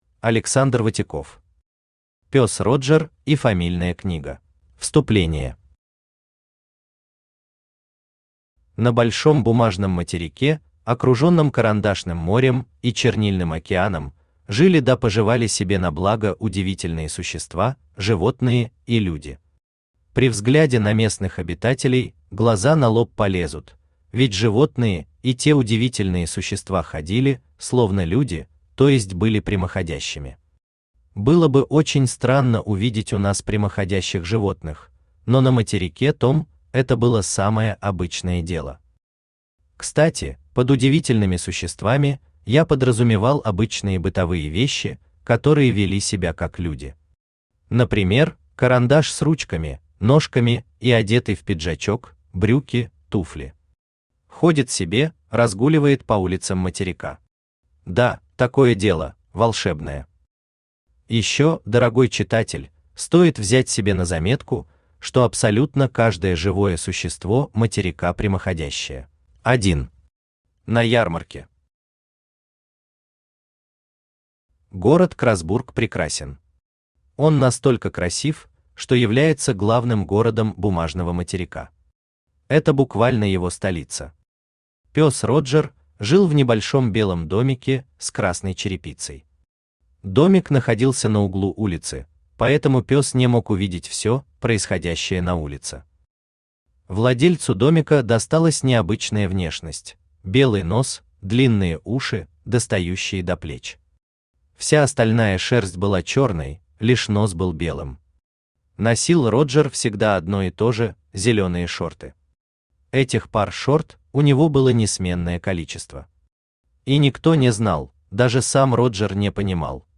Аудиокнига Пёс Роджер и фамильная книга | Библиотека аудиокниг